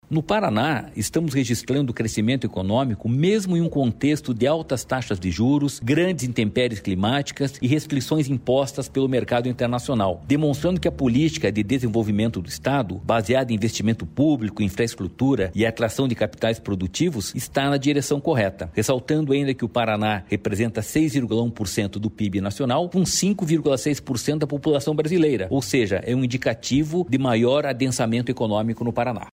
Sonora do presidente do Ipardes, Jorge Callado, sobre o crescimento do PIB no Paraná